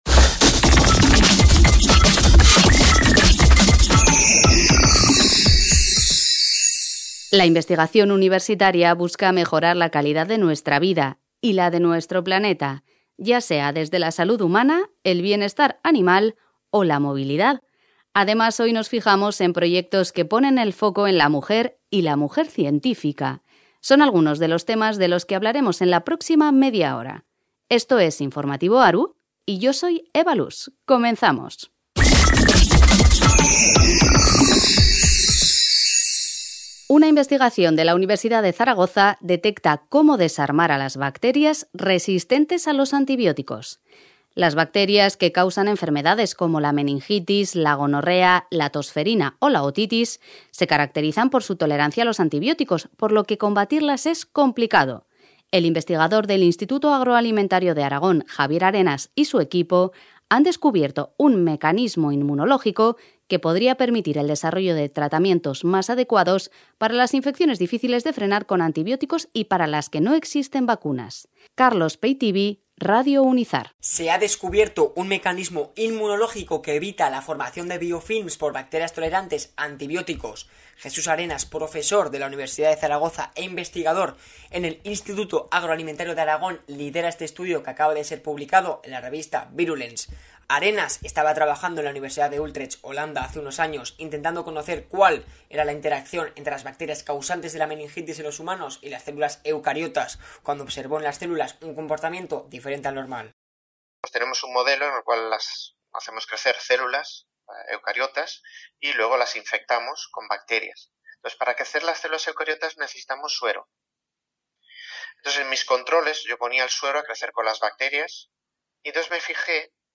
180222 Programa INFORMATIVO ARU